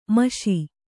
♪ maśi